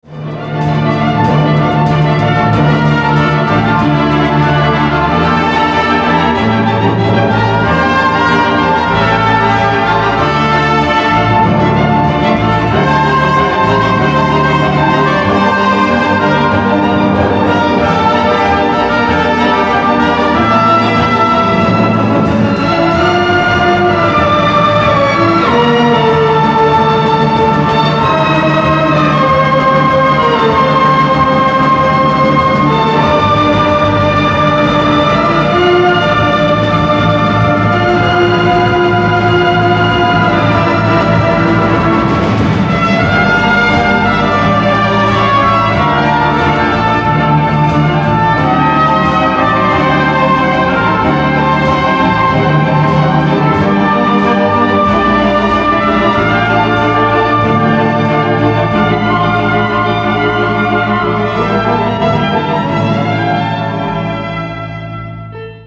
Чудесный инструментальный вариант песни